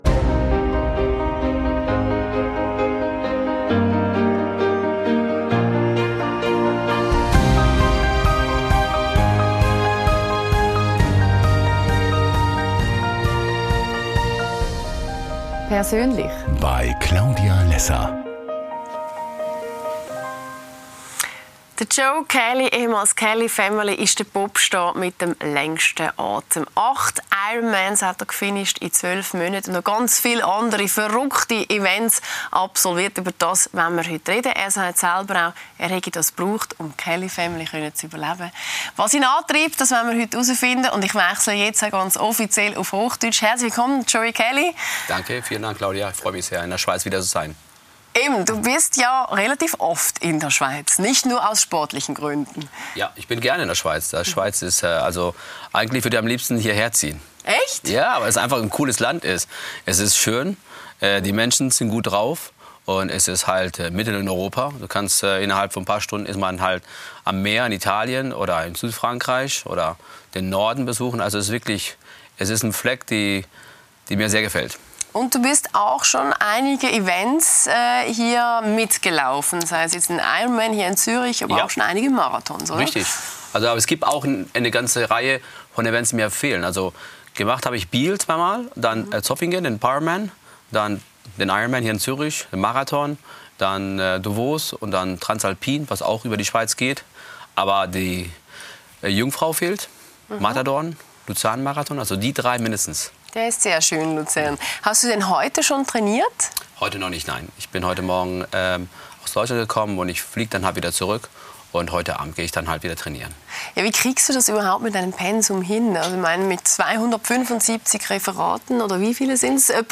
Lässer Classics zeigt die besten Talks aus den letzten zehn Jahren mit Claudia Lässer. In dieser Folge: Joey Kelly.
Die Talkshow